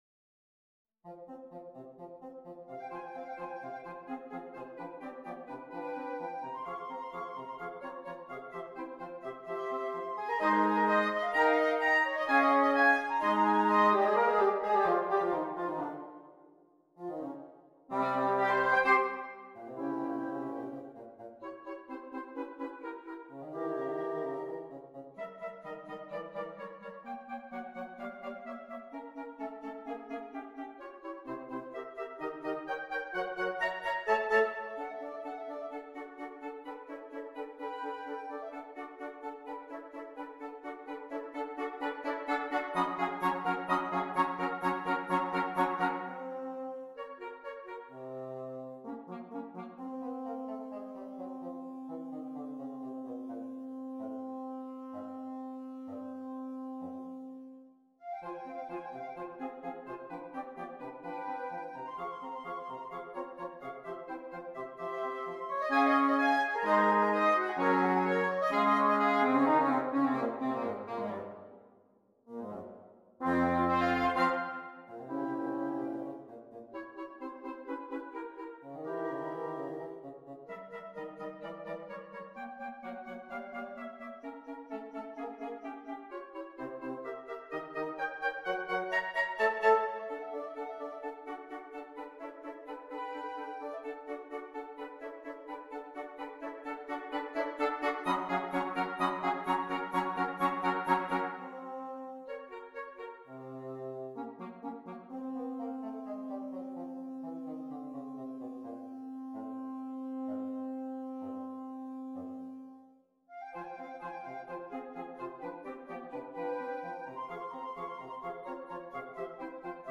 Interchangeable Woodwind Ensemble